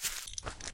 sounds / material / human / step / grass3.ogg
grass3.ogg